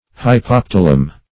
Search Result for " hypoptilum" : The Collaborative International Dictionary of English v.0.48: Hypoptilum \Hy*pop"ti*lum\, n.; pl.